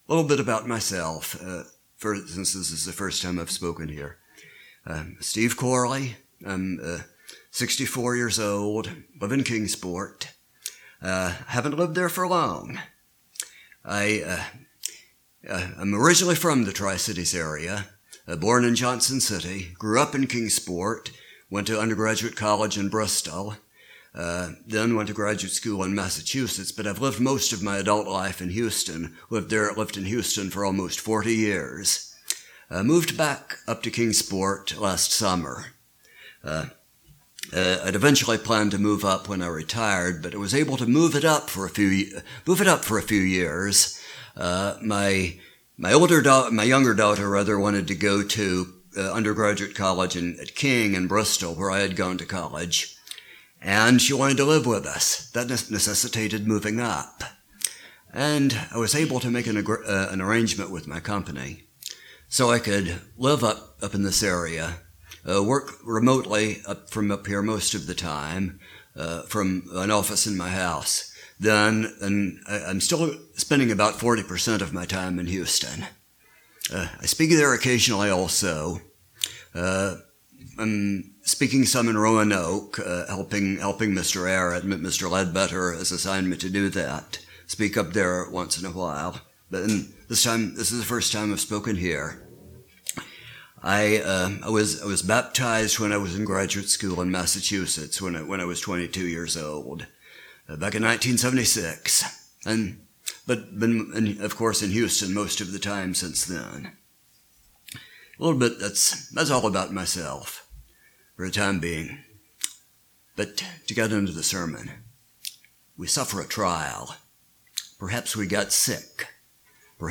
Sermons
Given in Kingsport, TN Knoxville, TN